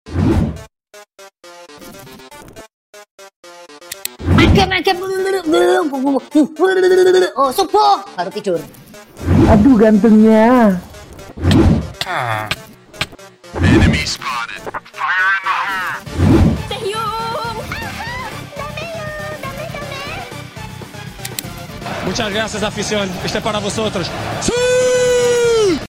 🎧 60+ Meme Sound Effect sound effects free download